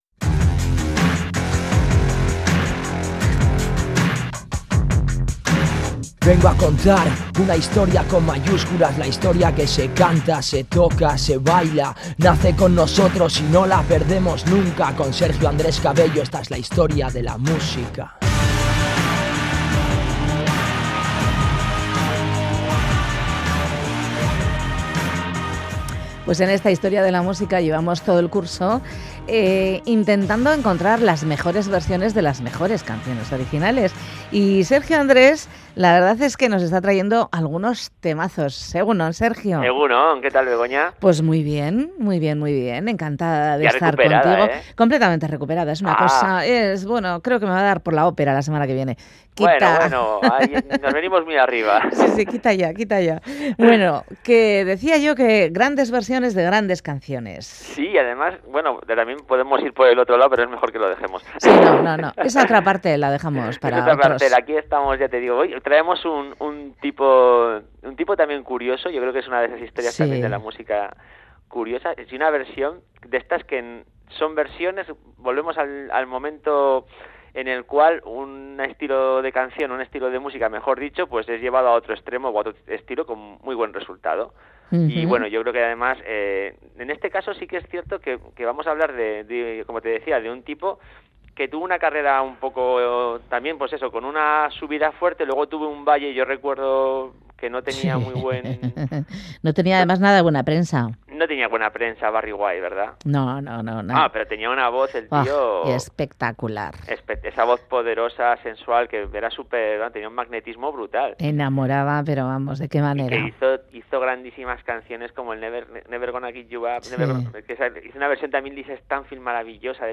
En cualquier caso ambas versiones invitan a bailar.